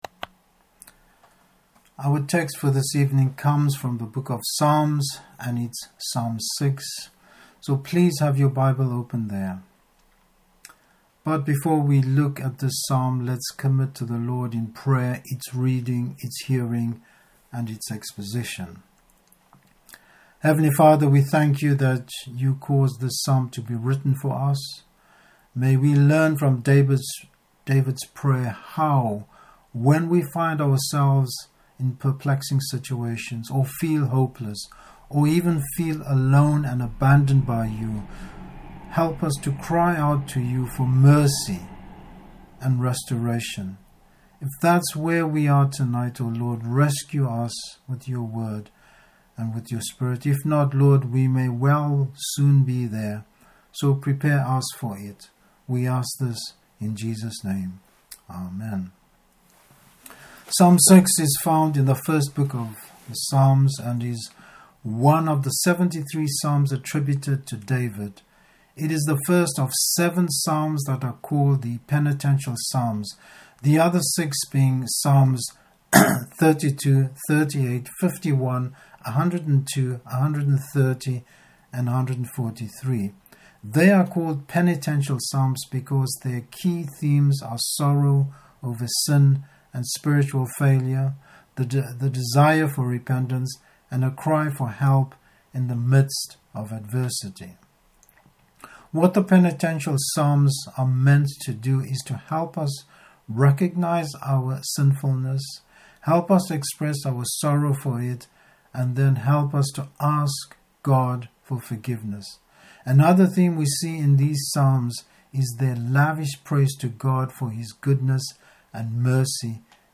Service Type: Evening Service Looking to the Lord in difficult times.